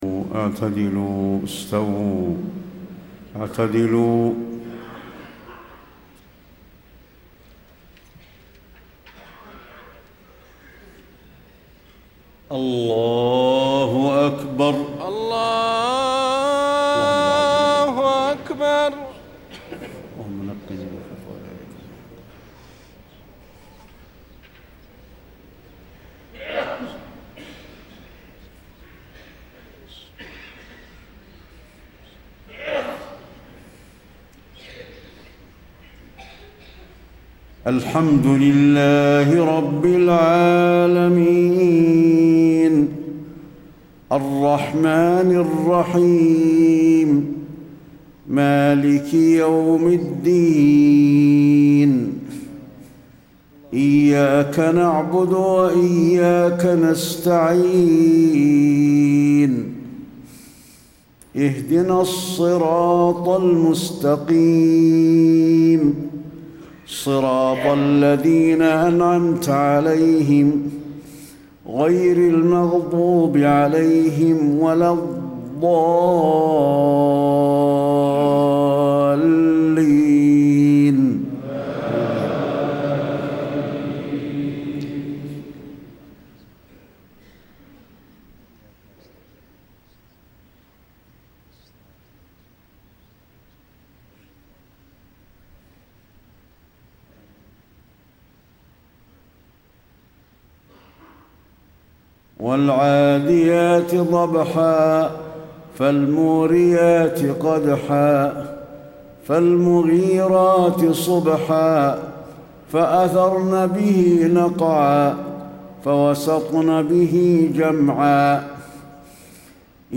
صلاة المغرب 8-7-1434هـ سورتي العاديات و القارعة > 1434 🕌 > الفروض - تلاوات الحرمين